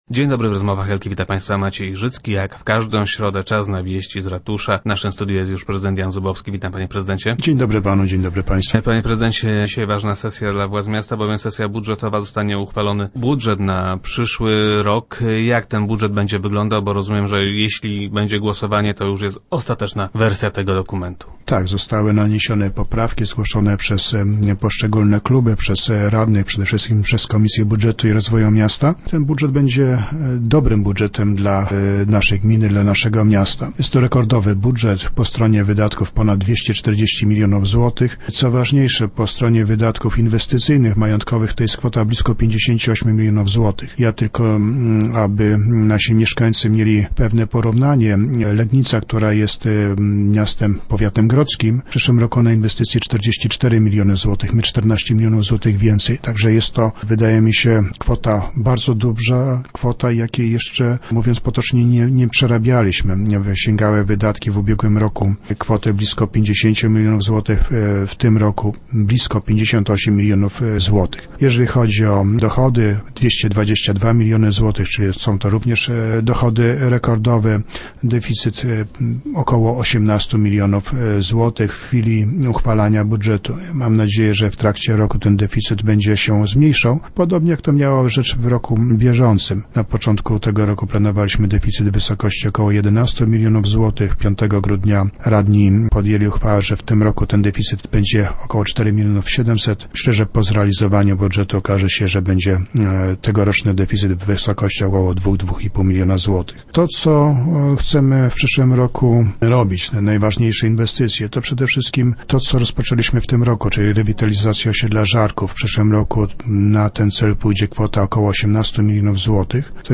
Zdaniem prezydenta Jana Zubowskiego 2011 rok był udany dla miasta i jego mieszkańców.
- Był to przede wszystkim rok normalnej pracy. Budżet został dobrze zrealizowany. Dochody wykonaliśmy w stu procentach. Wydatki niemal w stu procentach. Deficyt jest śladowy bo wysokości jednego procenta rocznego budżetu - mówił na radiowej antenie Jan Zubowski.